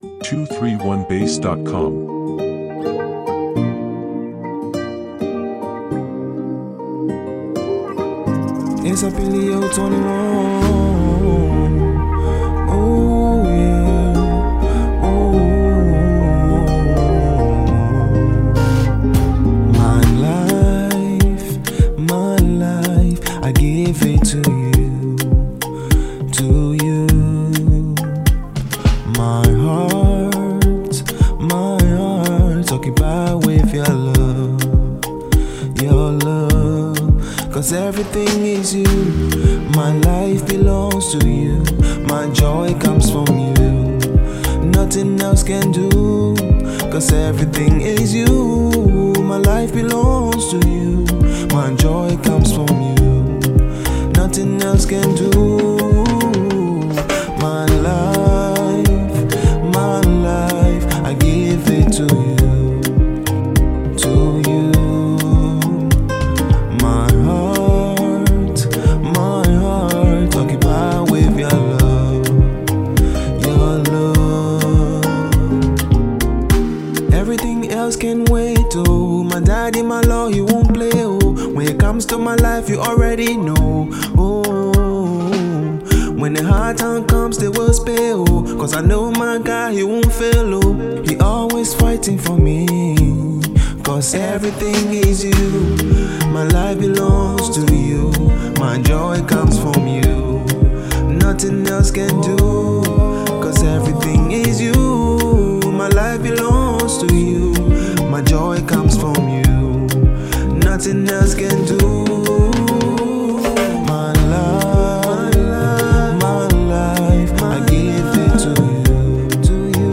Afro pop gospel
This soul-stirring track
the melody’s uplifting